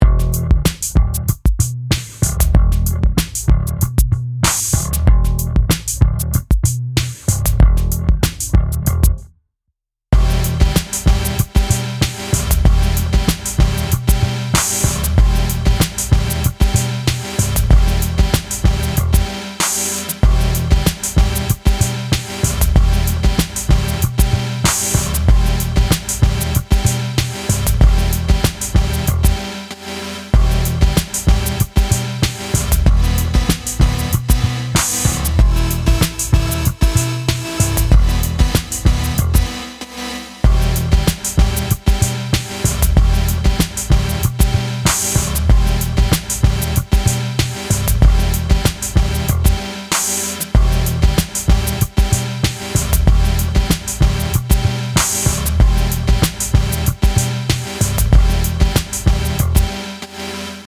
参加要件 テーマ 挑戦 長さ 8小節 BPM 95 締切 2026年4月末頃？？